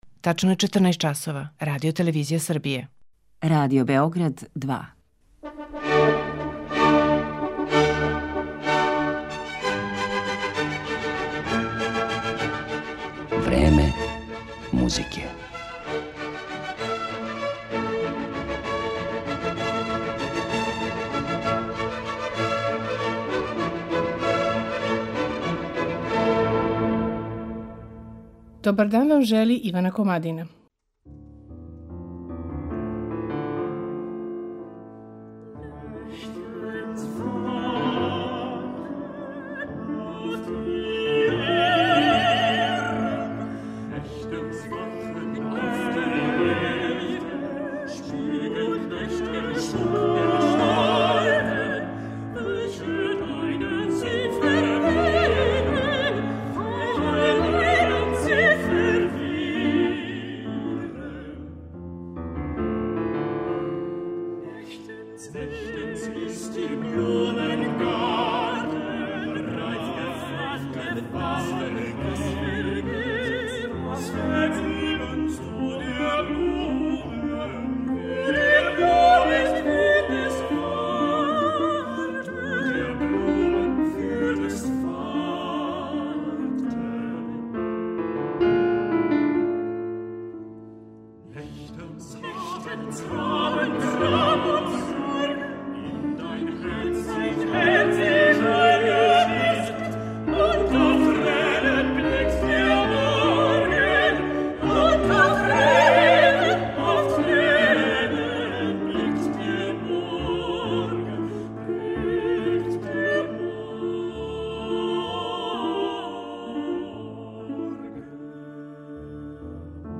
Део тог огромног дијапазона осликавања Месеца и ноћи у музици, осветлићемо у данашњем Времену музике, вокалном лириком Брамса, Шумана, Дебисија, Фореа, Шосона, Момпоуа, Дипарка. Њихове песме изводи ансамбл Myrthen.